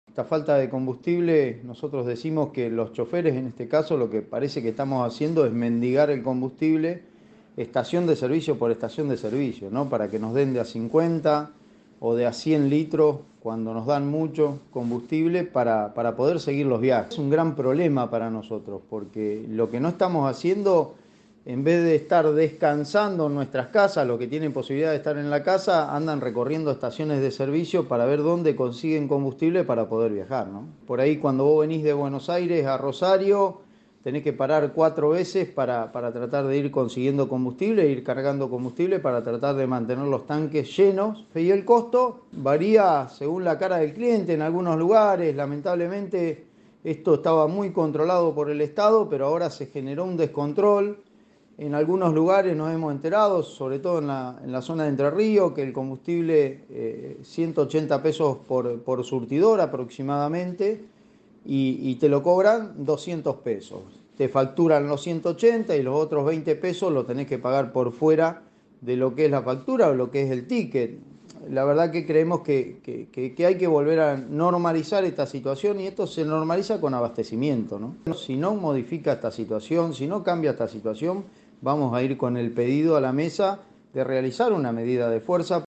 dialogó con el móvil de Cadena 3 Rosario, en Radioinforme 3